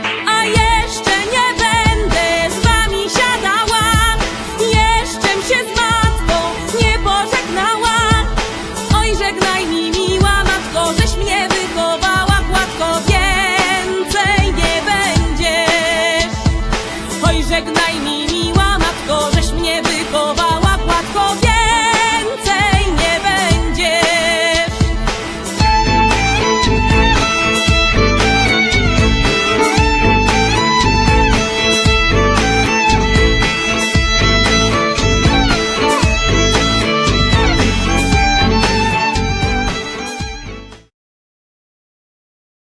śpiew, skrzypce
kontrabas
akordeon
altówka
perkusja
flet poprzeczny, saksofon sopranowy